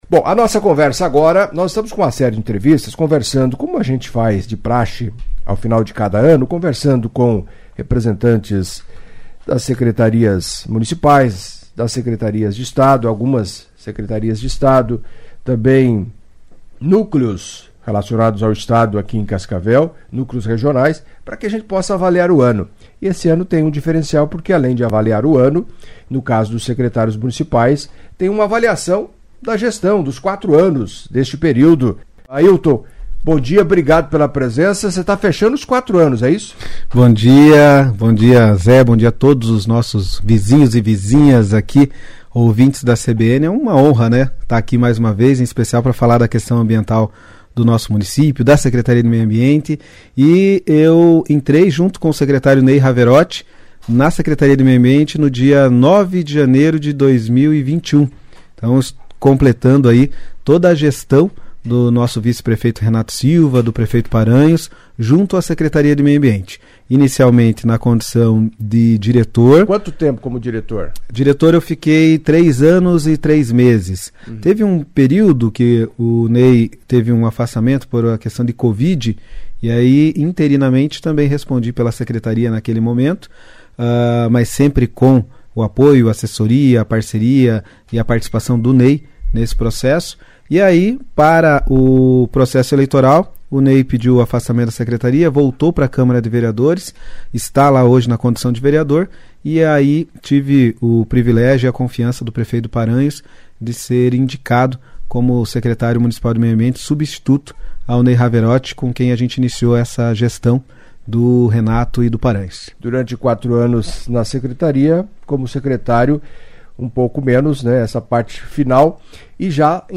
Em entrevista à CBN Cascavel nesta sexta-feira (20) Ailton Lima, secretário municipal de Meio Ambiente, falou dos avanços alcançados ao longo do ano e da gestão e destacou as ações e perspectivas de trabalho, a partir de janeiro, com a Administração da cidade sob o comando do prefeito Renato Silva, acompanhe.